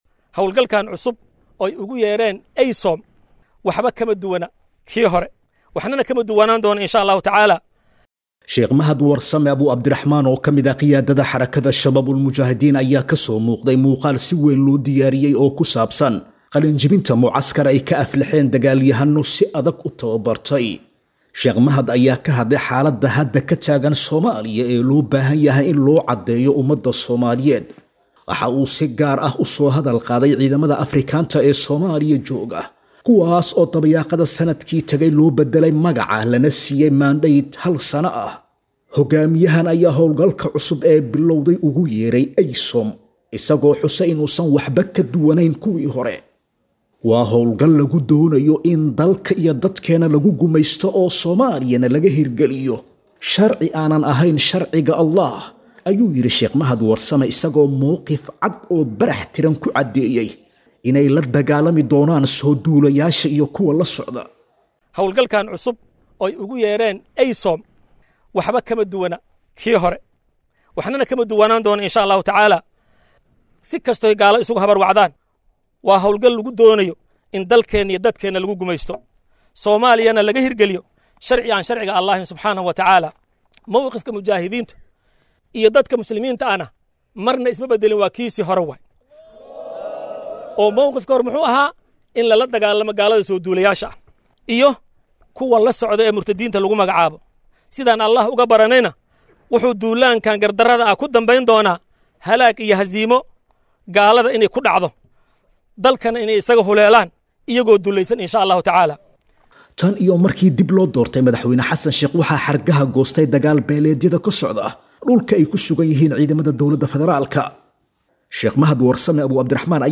[Warbixin].